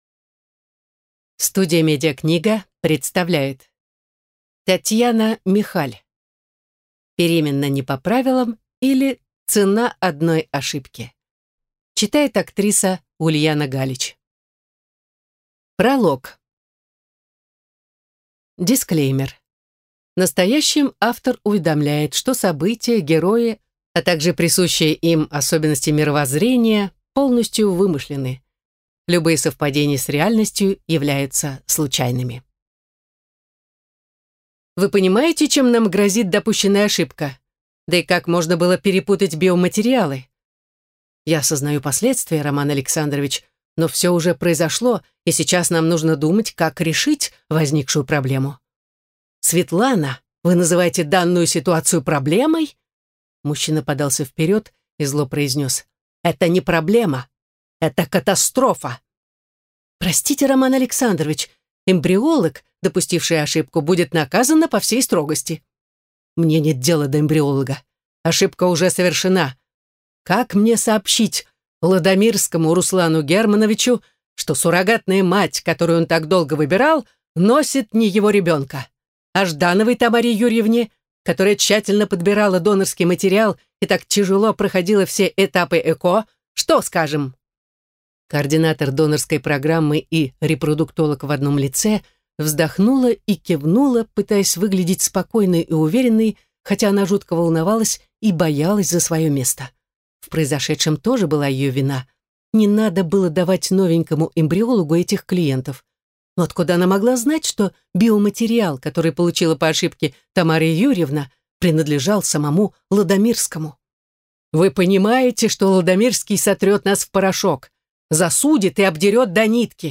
Аудиокнига Беременна не по правилам, или Цена одной ошибки | Библиотека аудиокниг